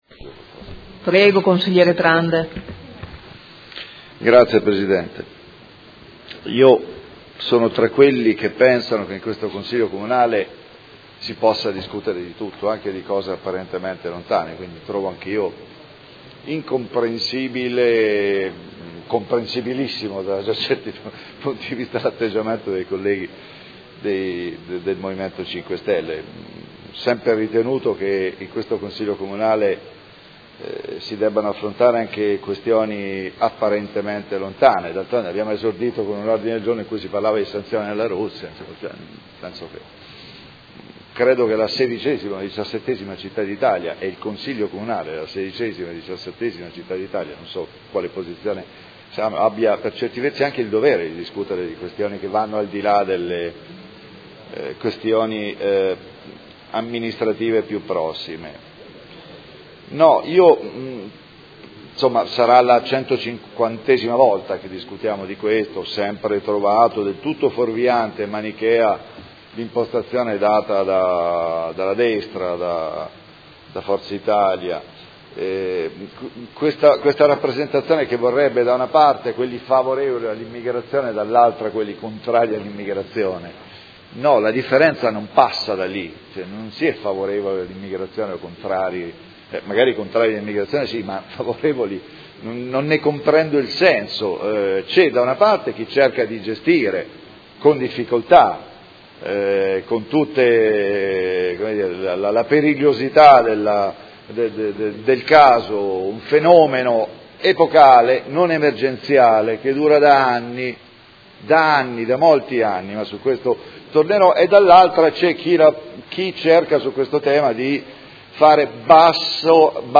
Seduta del 9/11/2017. Dibattito su Ordine del Giorno del Gruppo Forza Italia avente per oggetto: L’aumento costante degli arrivi di migranti richiede politiche chiare e decisive per limitare e regolamentare il flusso e interrompere la catena sempre più lunga dei morti in mare